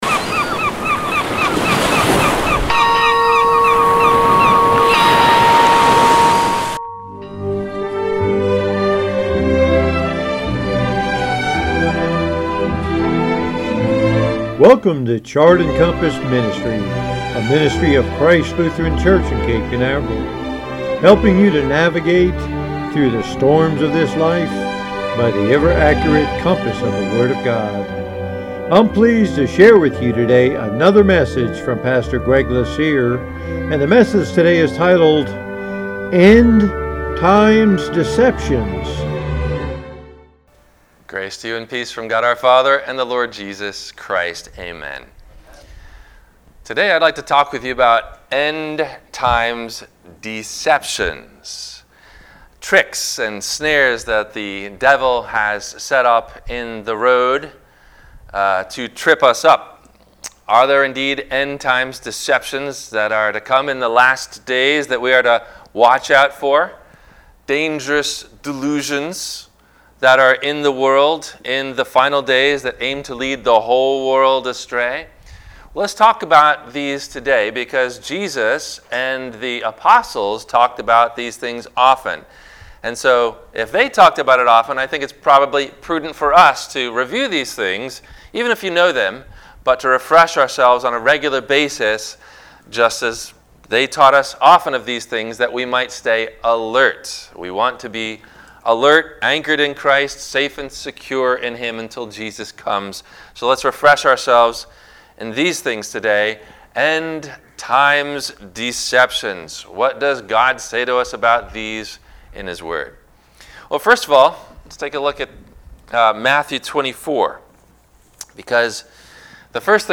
Psalm 91 – God’s Promise of Protection – WMIE Radio Sermon – September 28 2020
No Questions asked before the Radio Message.